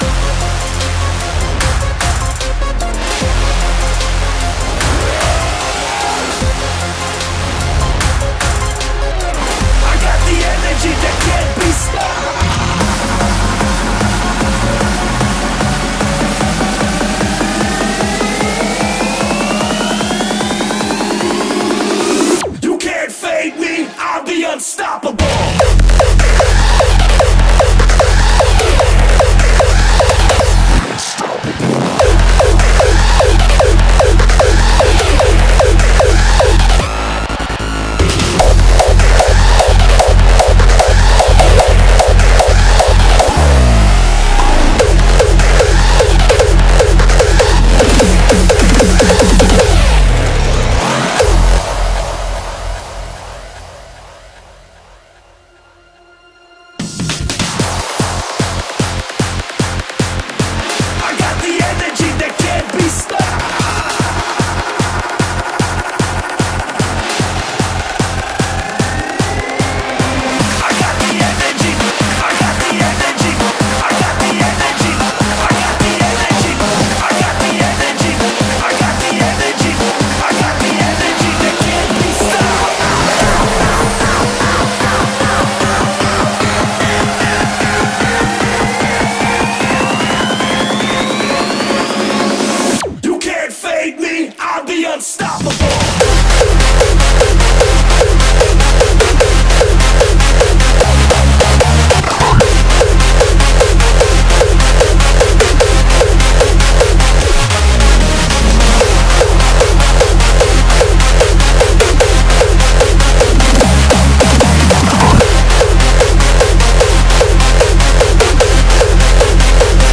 BPM150
Audio QualityPerfect (High Quality)
Comments[HARDSTYLE]